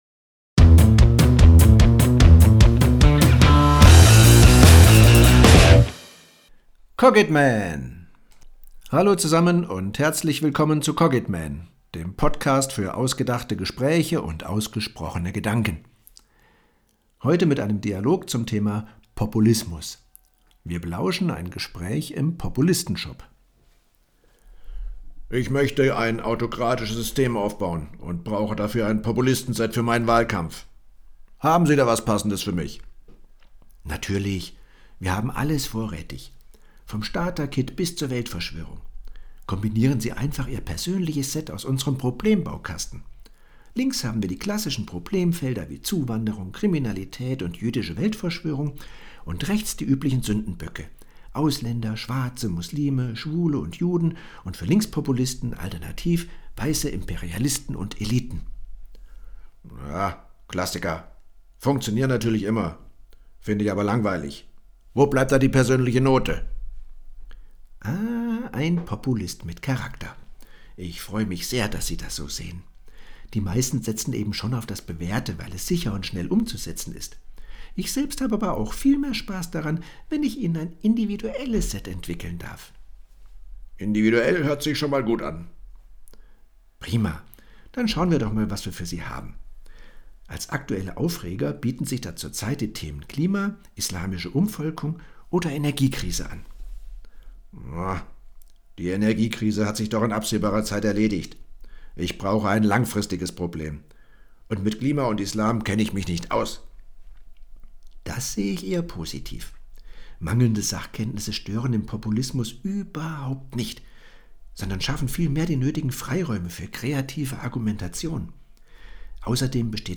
Dialog_Populistenshop.mp3